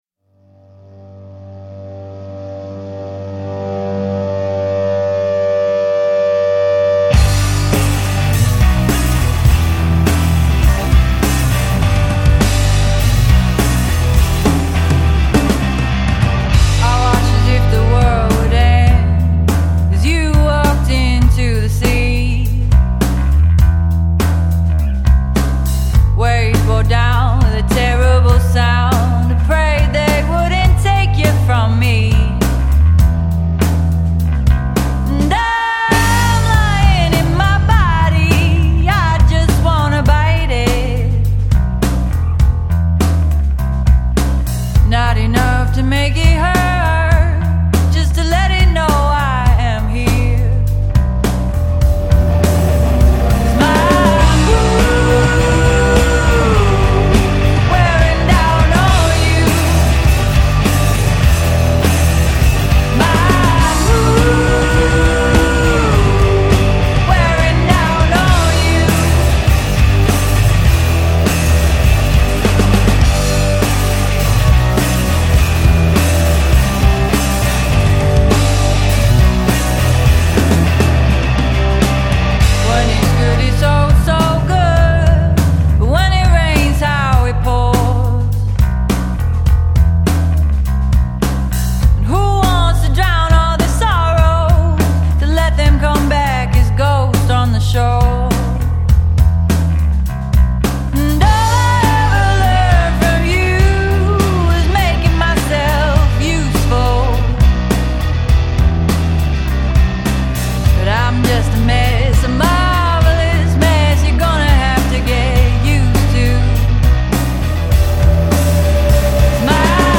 vocals, guitar
drums, vocals
bass, war siren